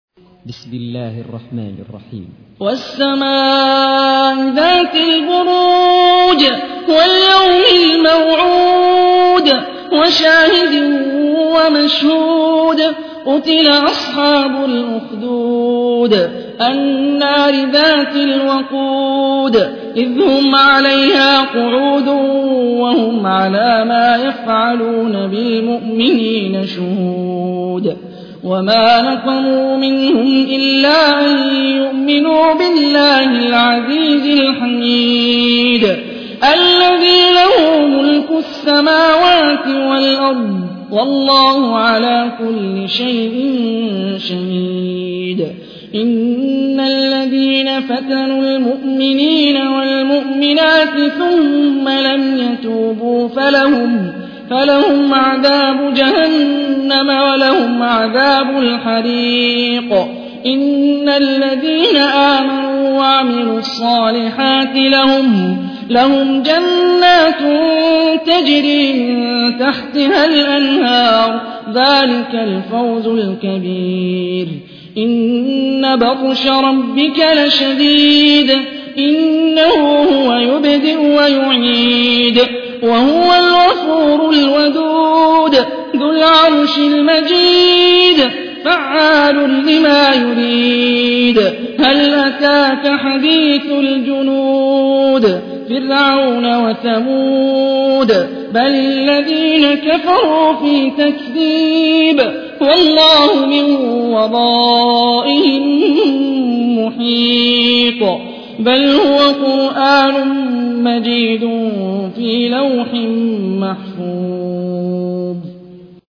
تحميل : 85. سورة البروج / القارئ هاني الرفاعي / القرآن الكريم / موقع يا حسين